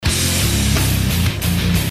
Come at me bro! (guitar).mp3